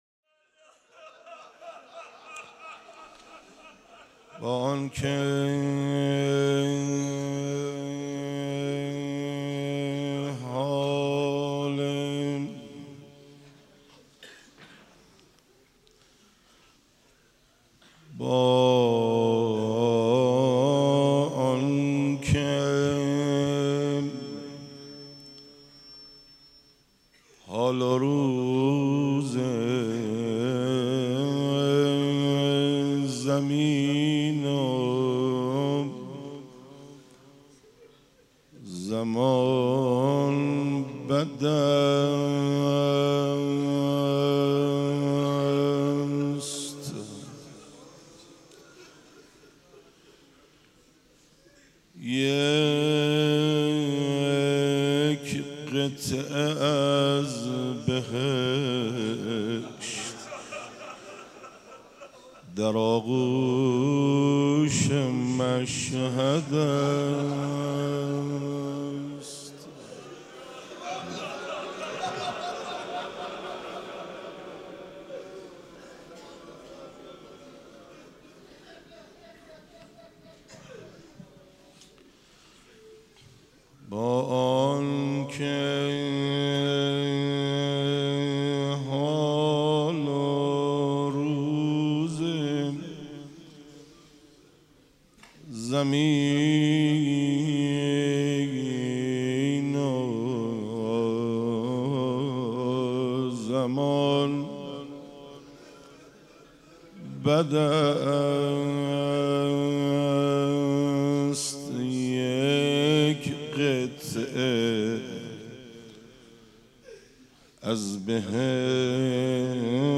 روضه امام رضا ع.mp3